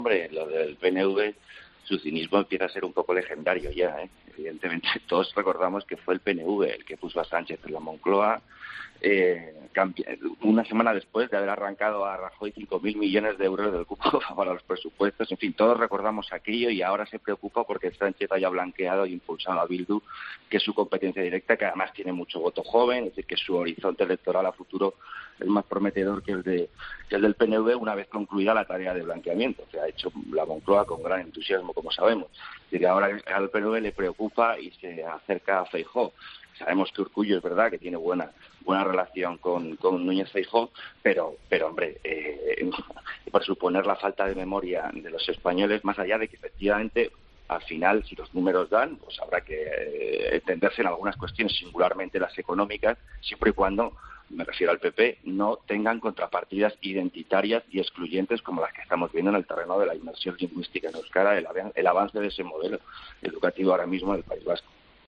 En el café de redacción de 'Herrera en COPE', Jorge Bustos ha señalado cuál puede ser el siguiente movimiento del Partido Nacionalista Vasco